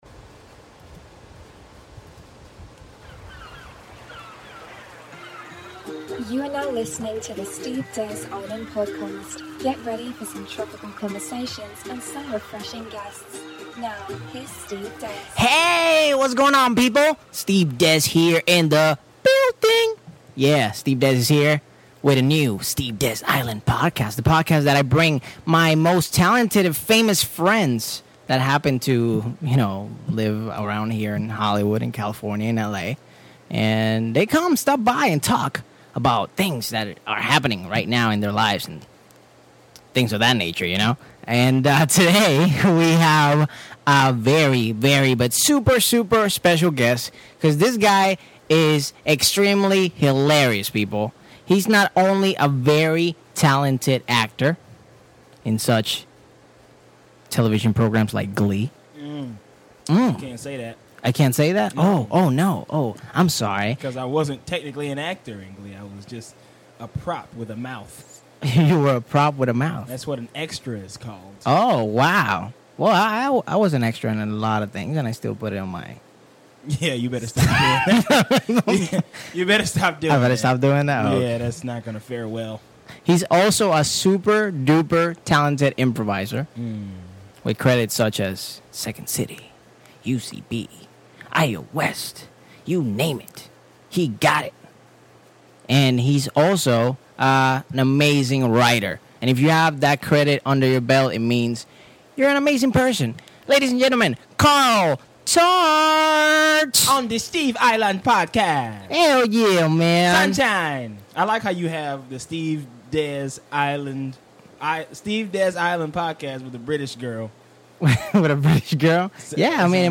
We play radio Dj's for different stations, we rap about welders and other one word suggestions, and we talk about why it is so cool to have a myspace music page.